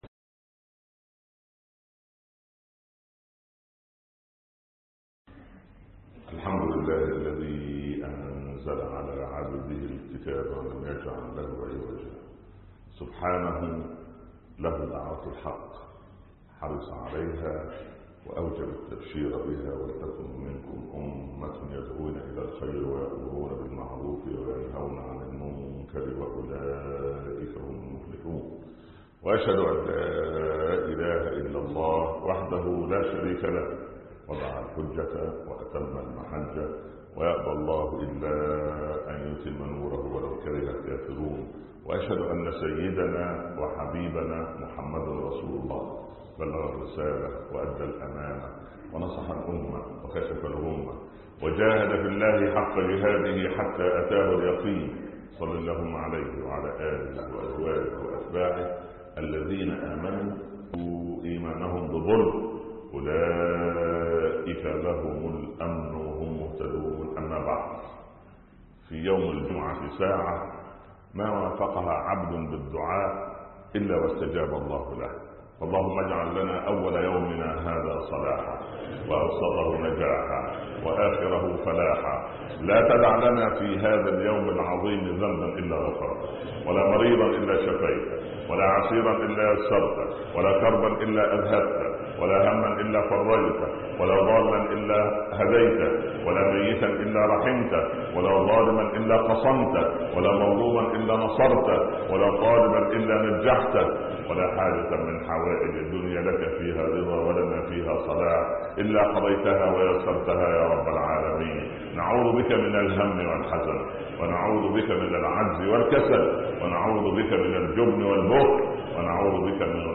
منظومة القيم (20/3/2015) خطب الجمعه - الشيخ عمر بن عبدالكافي